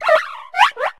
pawmo_ambient.ogg